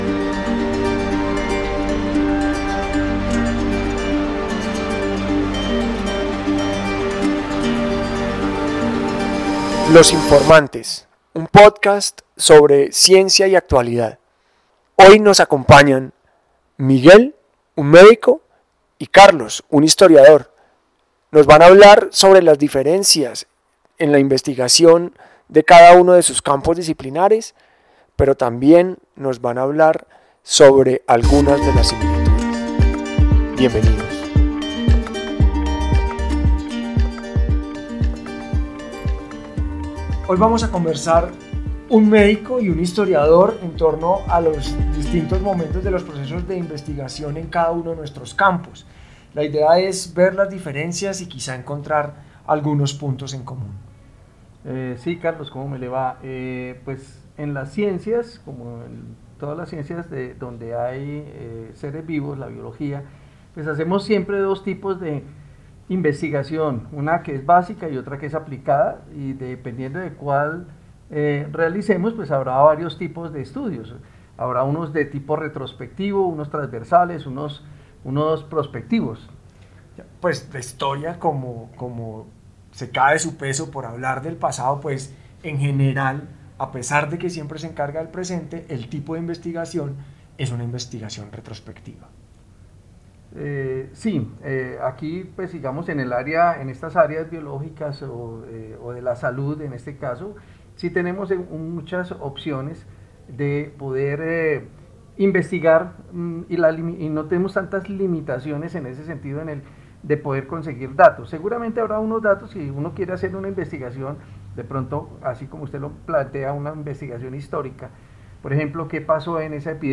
Los saberes no solo se diferencian en sus contenidos sino en la naturaleza de los datos con que trabajan, el modo en que los recolectan y relacionan y también desde el punto de vista de sus objetivos. Te invitamos a escuchar un Podcast en el que un médico y un historiador se reúnen para conversar sobre las diferencias y similitudes en sus formas de investigar.